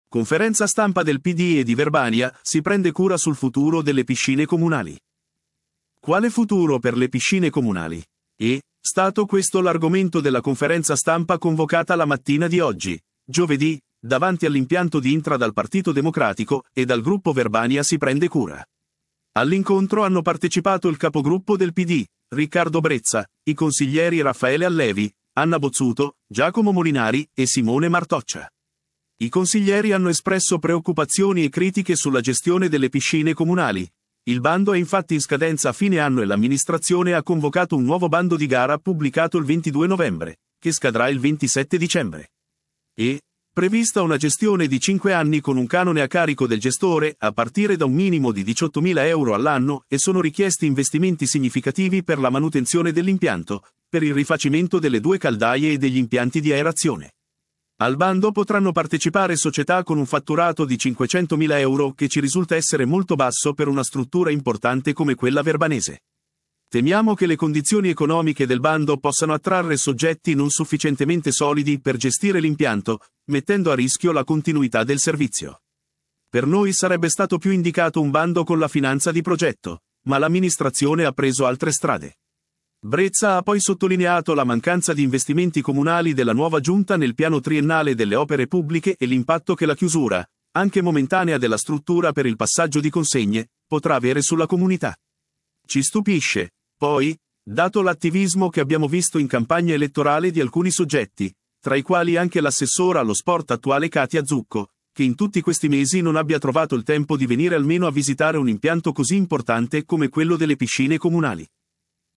Conferenza stampa del Pd e di Verbania si prende cura sul futuro delle piscine comunali
E’ stato questo l’argomento della conferenza stampa convocata la mattina di oggi, giovedì, davanti all’impianto di Intra dal Partito democratico e dal gruppo Verbania si prende cura.